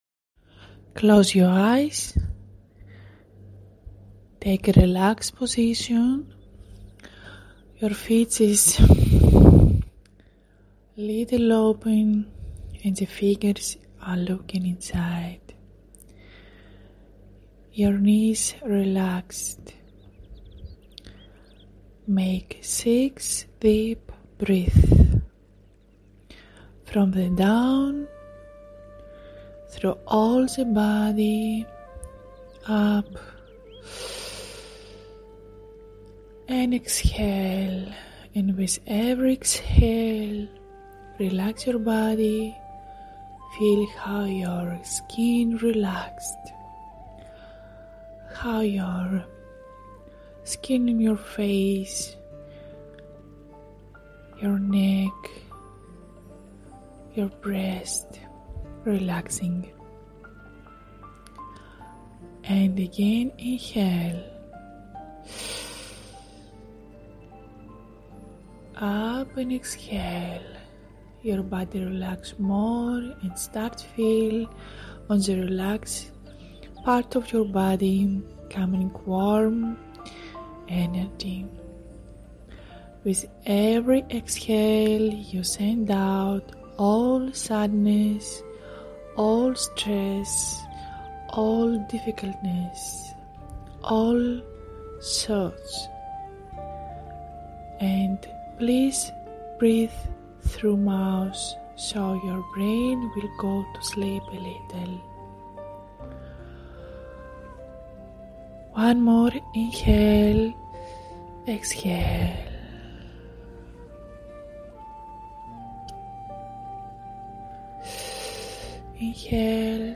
Get audio meditation for free
BREATHING-Harmony-of-energy-in-ENGLISH-with-music.mp3